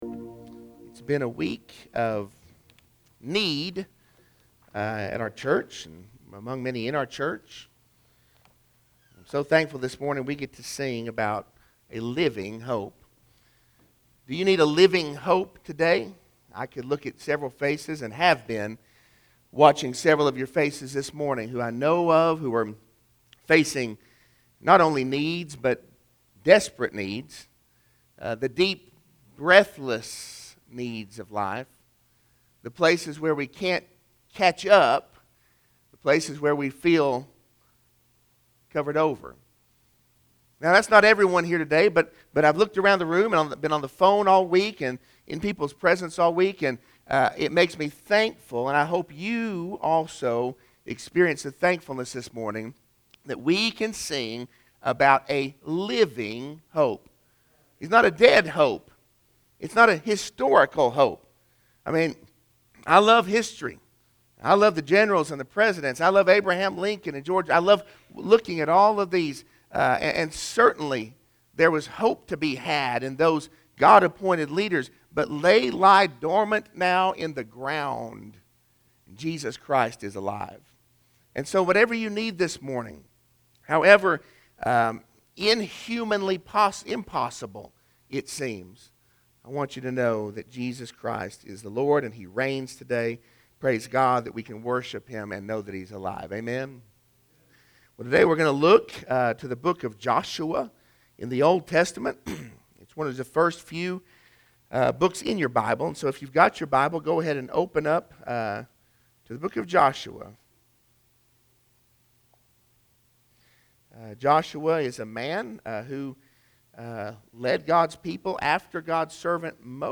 Sermon-8-15-21.mp3